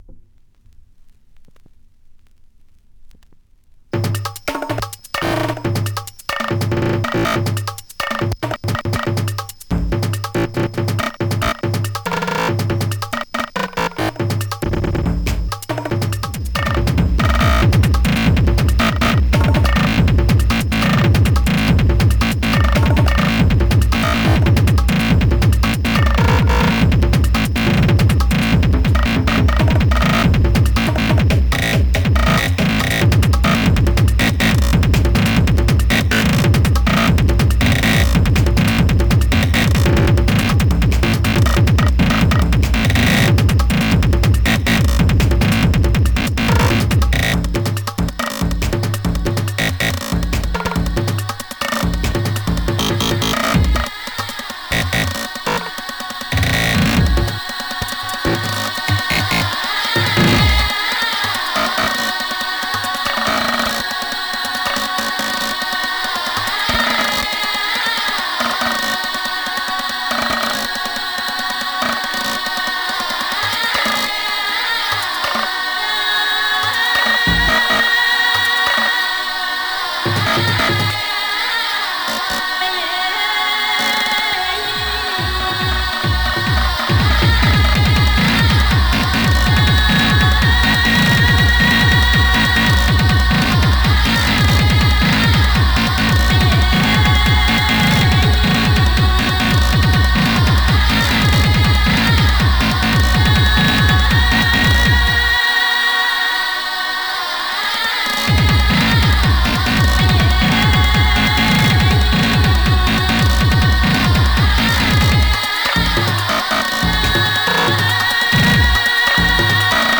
Yo, dernier mix hardstyle jumpstyle.